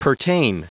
Prononciation du mot pertain en anglais (fichier audio)
Prononciation du mot : pertain